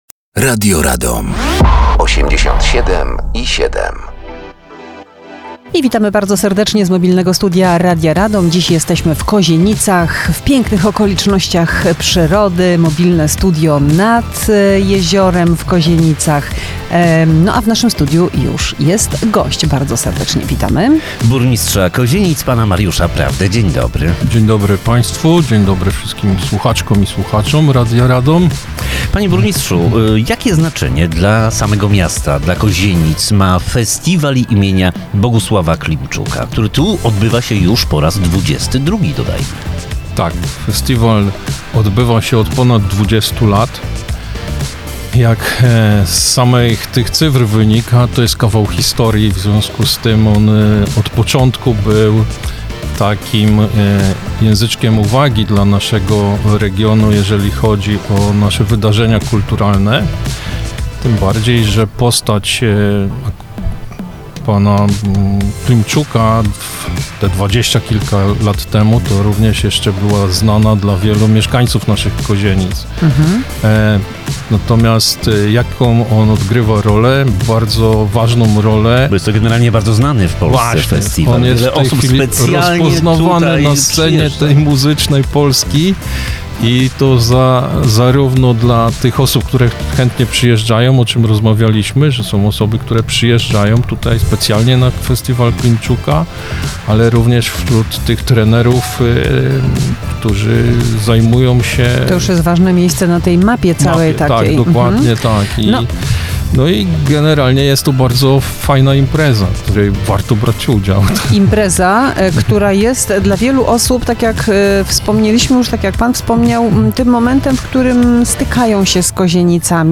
Mobilne Studio Radia Radom na XXII Festiwal Muzyki Rozrywkowej im. Bogusława Klimczuka.
Gościem jest Mariusz Prawda Burmistrz Kozienic